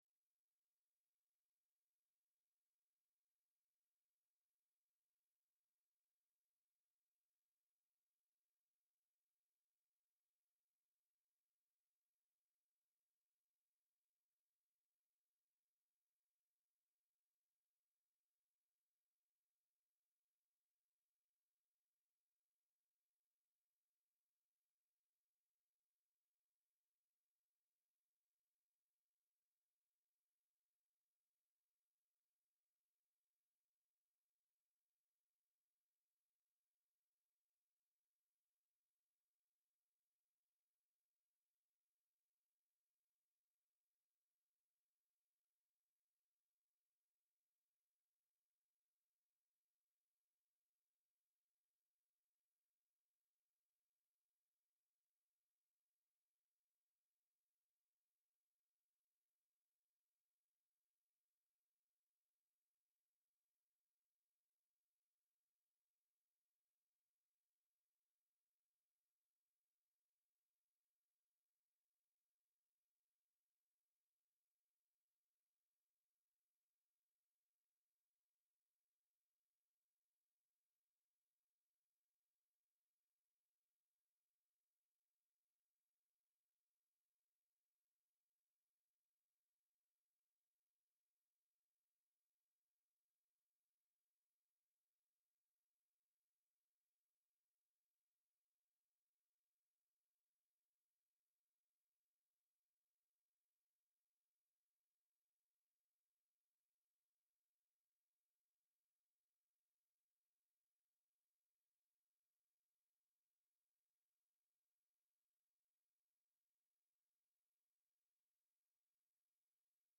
A message from the series "Matthew." Matthew 25:14-30